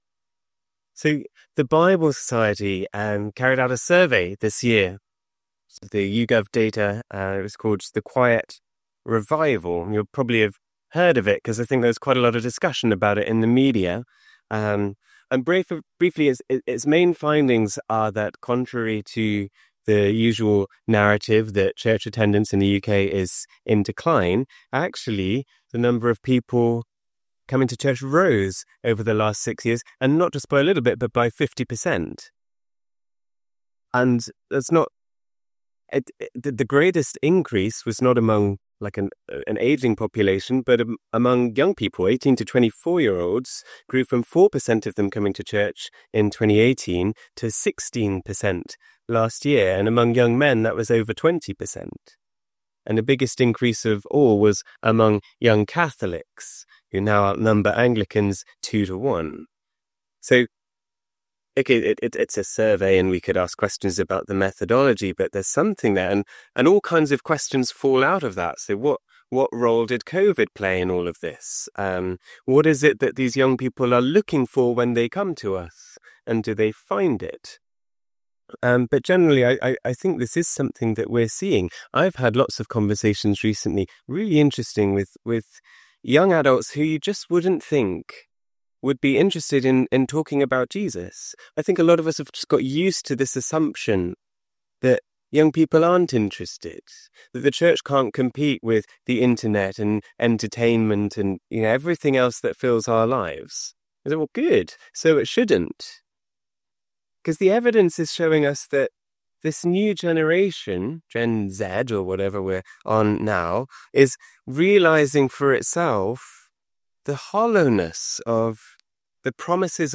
14th_sunday_homily.mp3